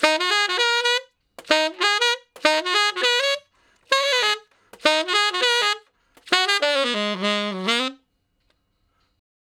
068 Ten Sax Straight (Ab) 01.wav